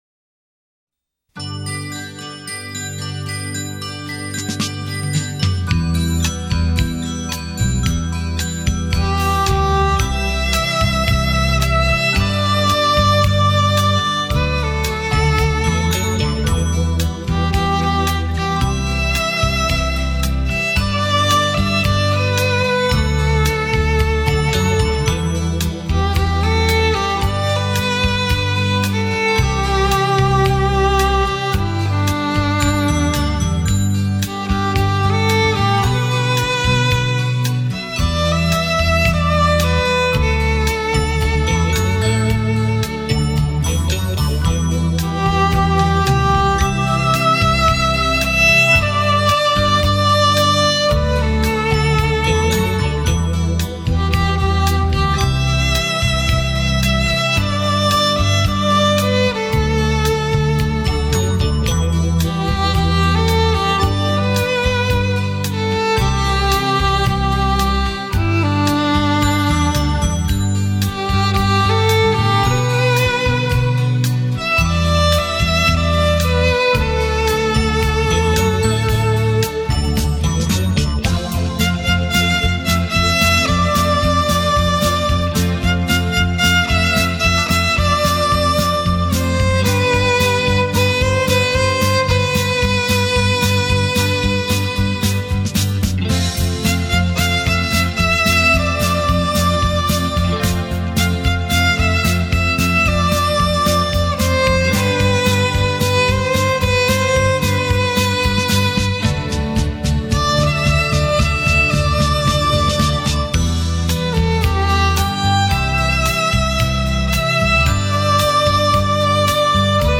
唱片类型：纯音乐